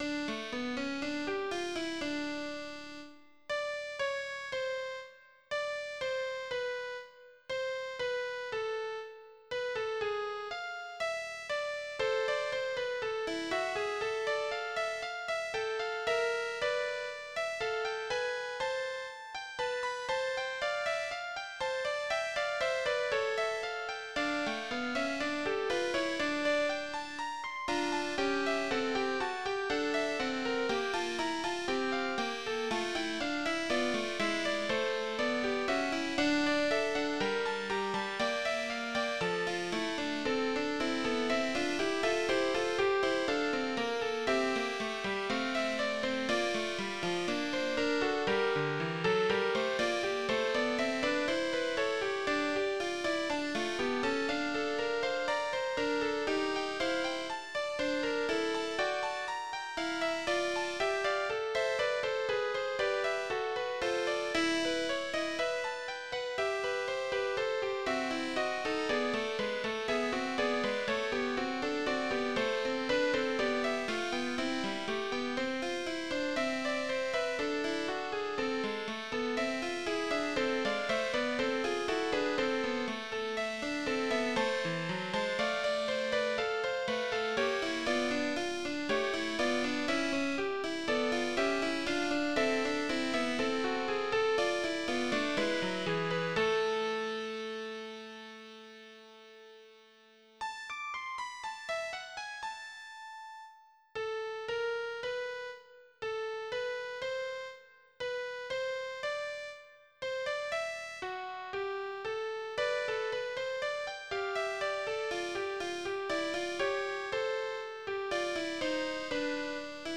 Trio-sonata style fugue